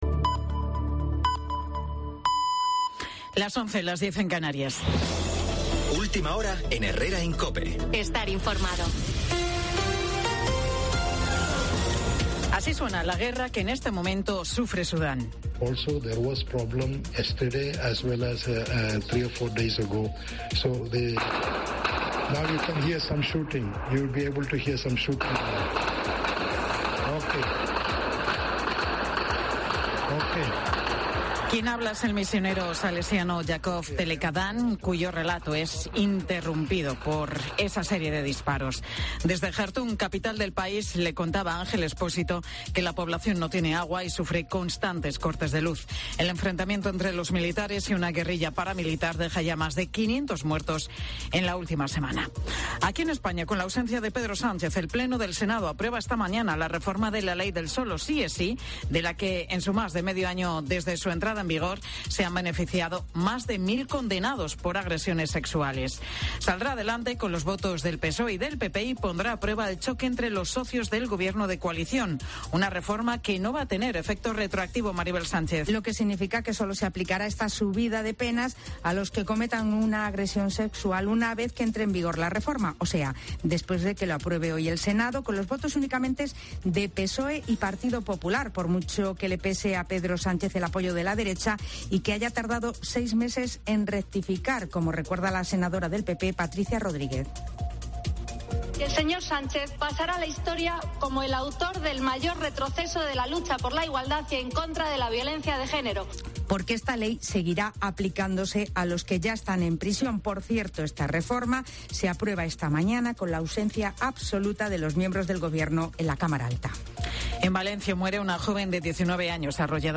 Tertulia de Sector Primario en COPE Canarias, miércoles 26 de abril de 2023